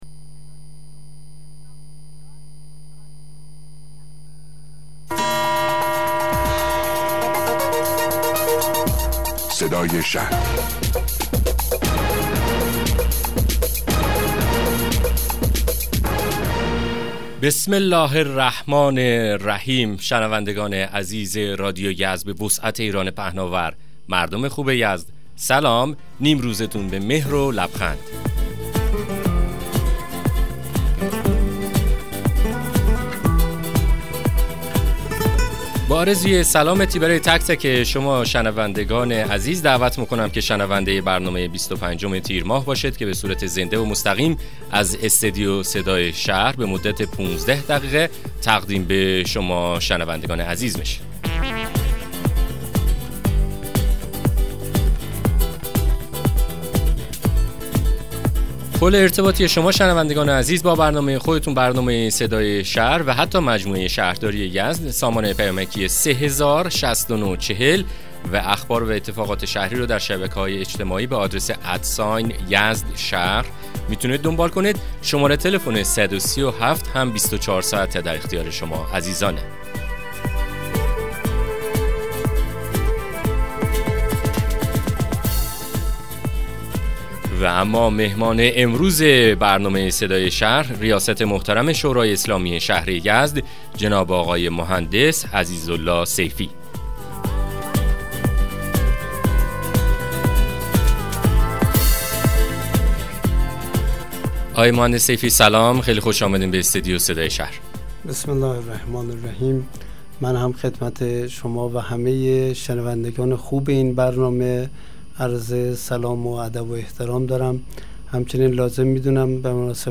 مصاحبه رادیویی برنامه صدای شهر با حضورعزیزاله سیفی عضو شورای اسلامی شهر یزد